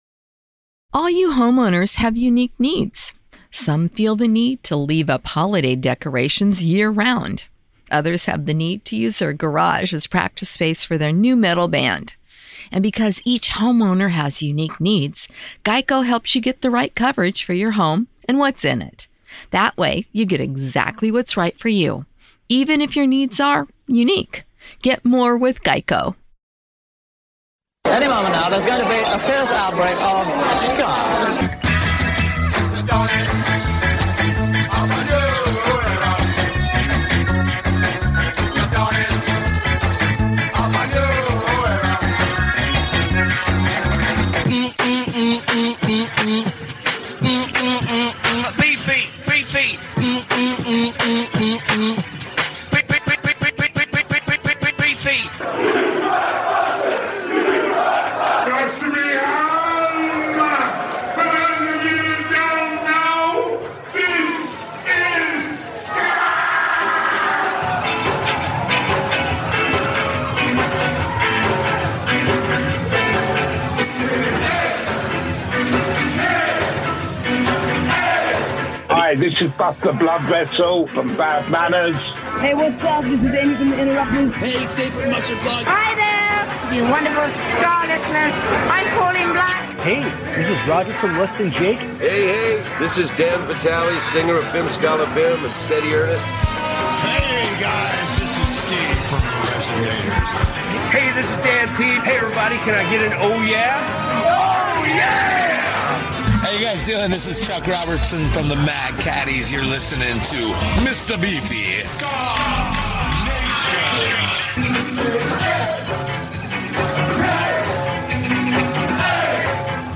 **SKA NATION RADIO - THE WORLD'S #1 SKA SHOW - FOR YOUR LISTENING PLEASURE **